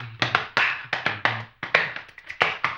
HAMBONE 04.wav